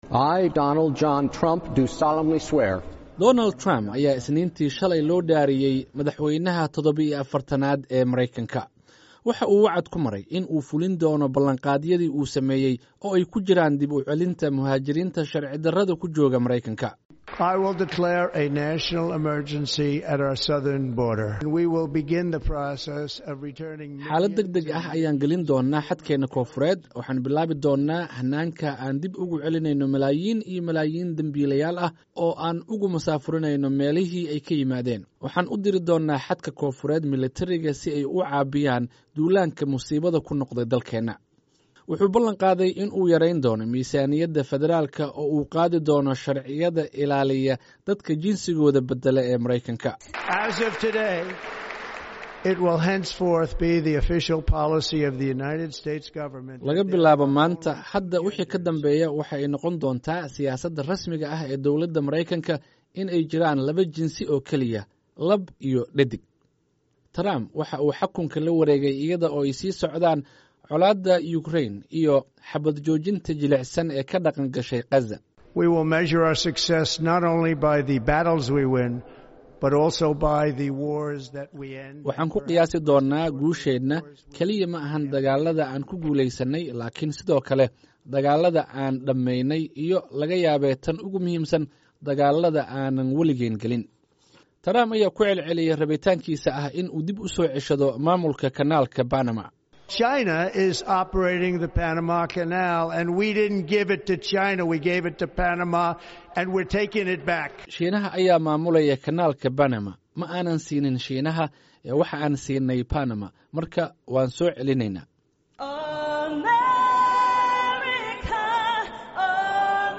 warbixintan